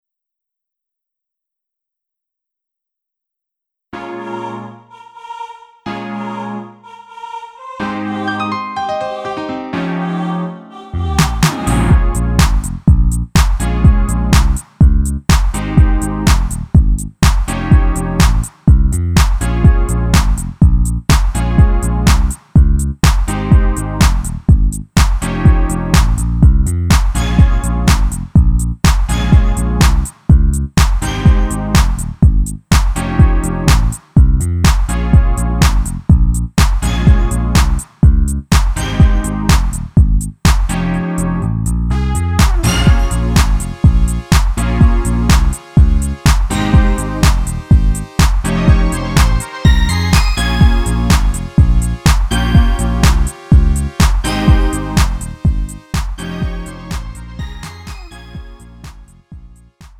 음정 -1키 3:06
장르 구분 Lite MR